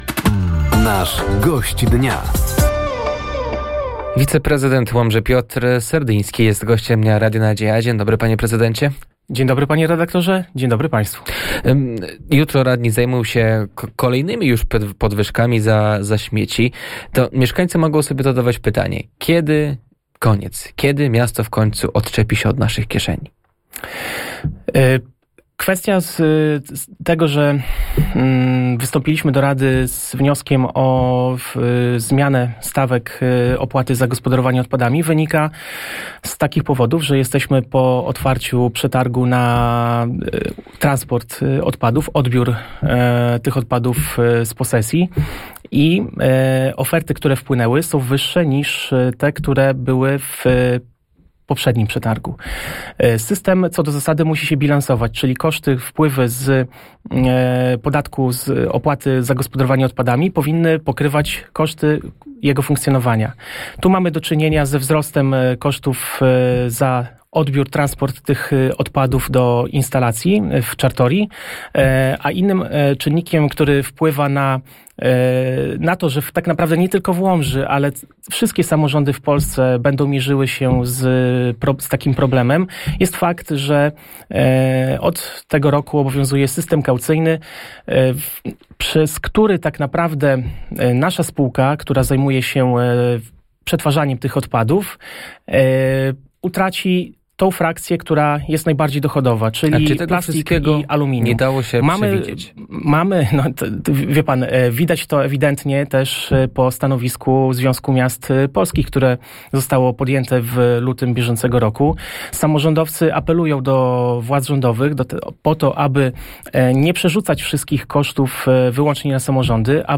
Gościem Dnia Radia Nadzieja był wiceprezydent Łomży Piotr Serdyński. Tematem rozmowy były podwyżki opłat za śmieci, realizowane inwestycje w mieście oraz spotkanie z mieszkańcami w sprawie parkingu przy szpitalu.